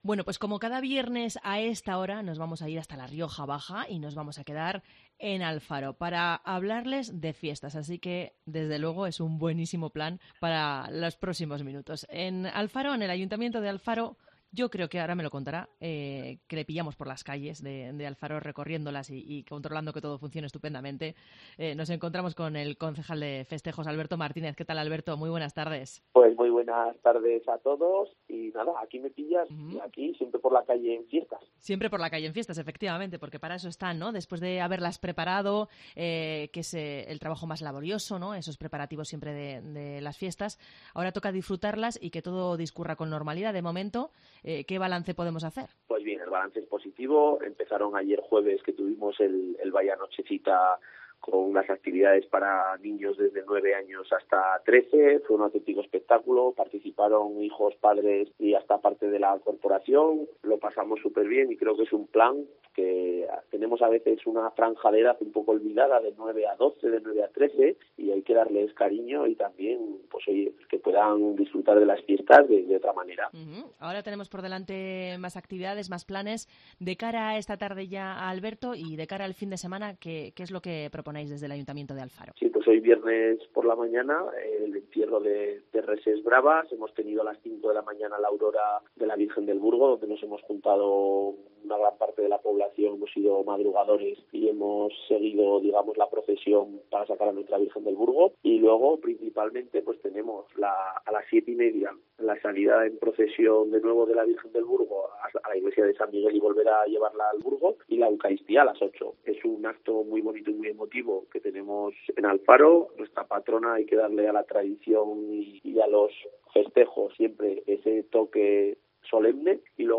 El concejal de Festejos del Ayuntamiento de Alfaro en los micrófonos de COPE
El concejal de Festejos del Ayuntamiento de Alfaro, Alberto Martínez, ha pasado por los micrófonos de COPE para hacer balance de las Fiestas en honor a la Virgen del Burgo.
Escucha aquí la entrevista al completo para conocer cómo se han desarrollado los primeros actos del programa festivo y cuáles son las actividades que se celebrarán durante este fin de semana en Alfaro.